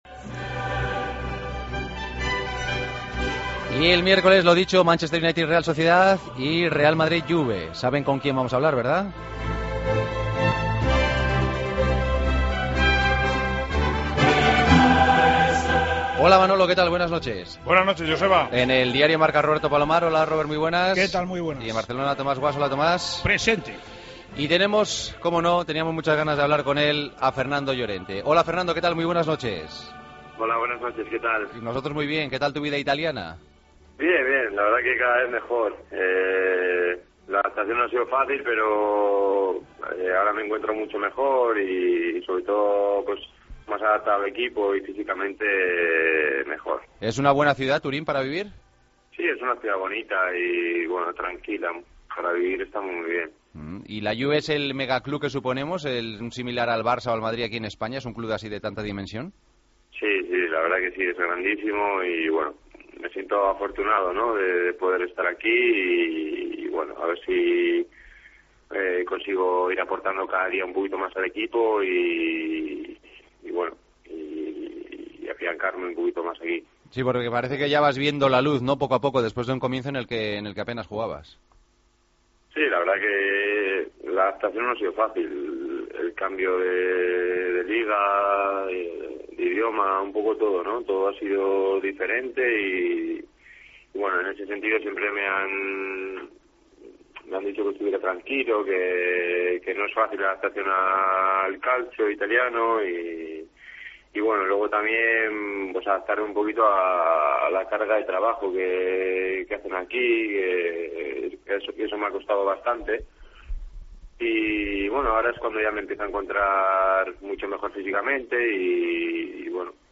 Entrevista a Fernando Llorente, en El Partido de las 12